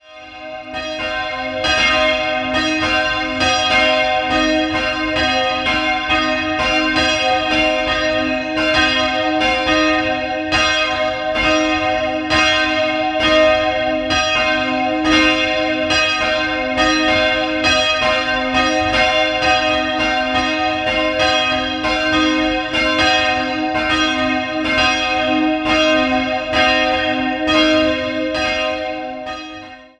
Seit 1985 komplettiert eine Orgel die Ausstattung. 2-stimmiges Geläut: c''-es'' Die Glocken wiegen 210 und 280 kg und wurden im Jahr 1980 gegossen.